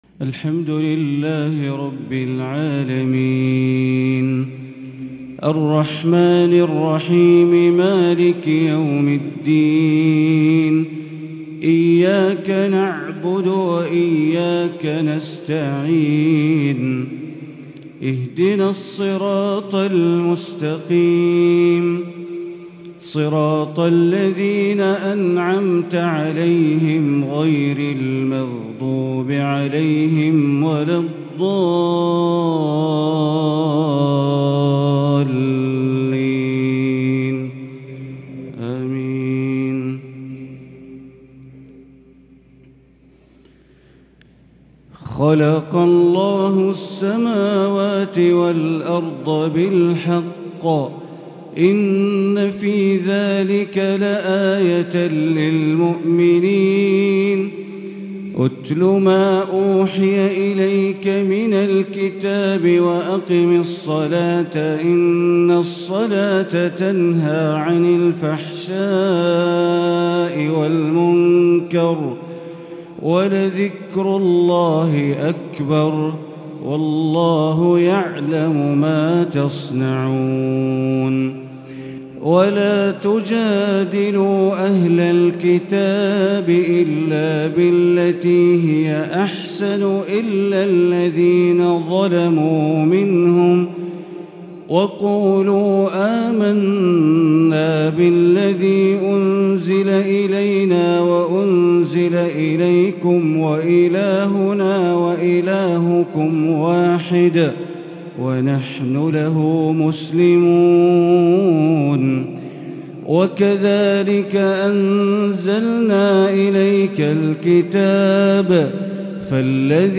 فجر الخميس ٤ شوال ١٤٤٣هـ من سورة العنكبوت | Fajr prayer from surat AlAnkabut 5-5-2022 > 1443 🕋 > الفروض - تلاوات الحرمين